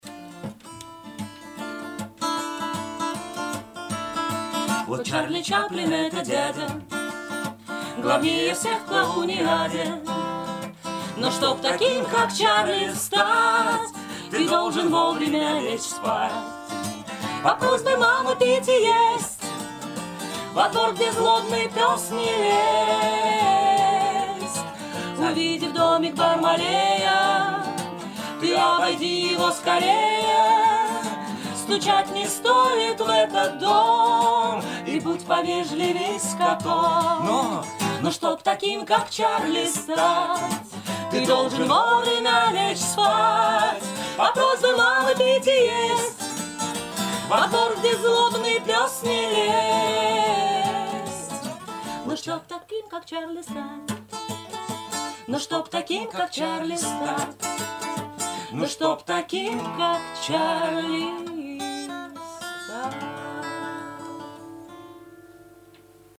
Треки, исполненные вживую в Доме радио: